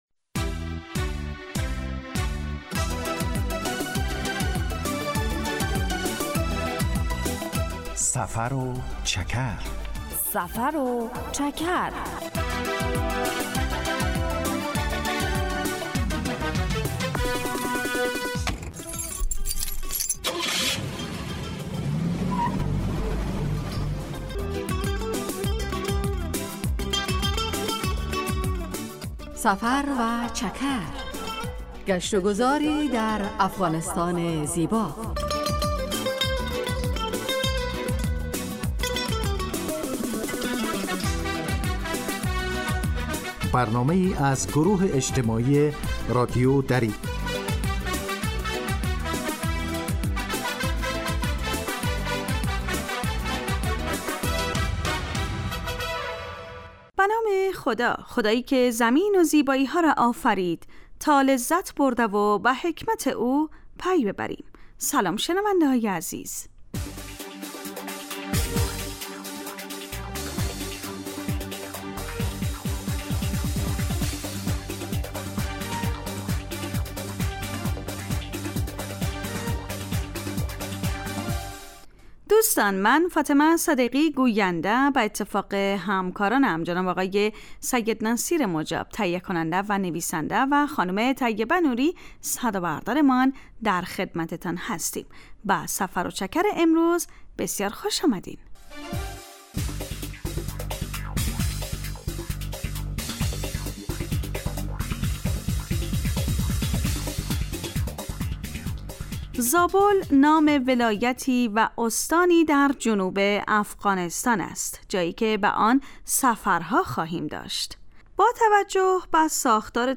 سفر و چکر برنامه ای 15 دقیقه از نشرات رادیو دری است که به معرفی ولایات و مناطق مختلف افغانستان می پردازد.
در سفر و چکر ؛ علاوه بر معلومات مفید، گزارش و گفتگو های جالب و آهنگ های متناسب هم تقدیم می شود.